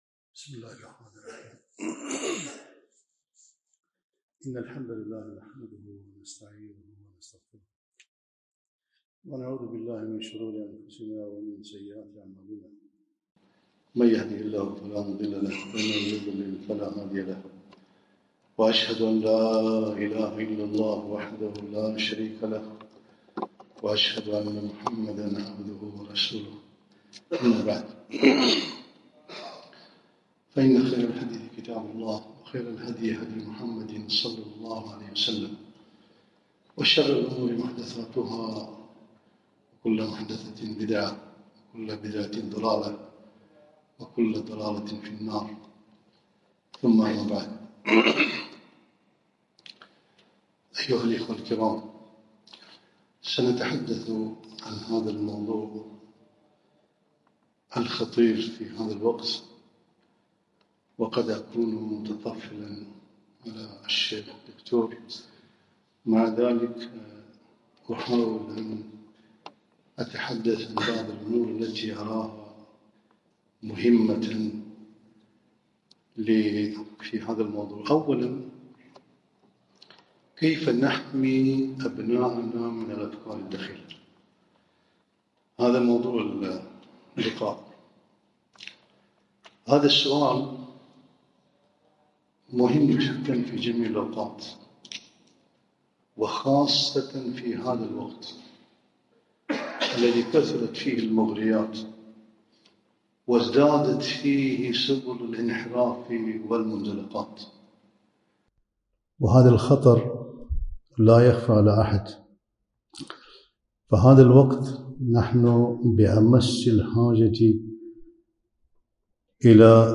محاضرة - كيف نحمي أبناءنا من الأفكار الدخيلة